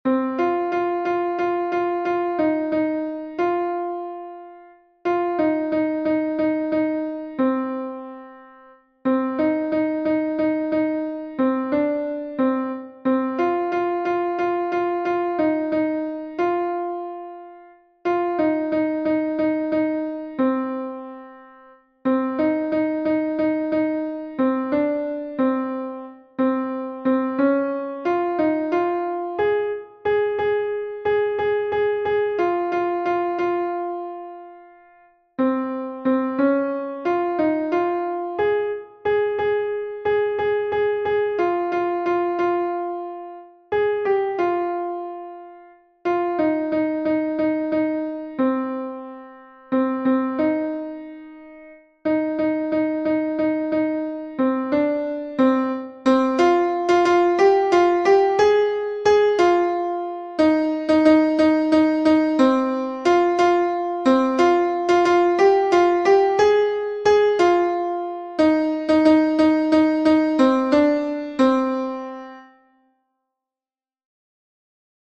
Fichier son alto 2
Nerea-izango-zen-Laboa-alto-2-V3-1.mp3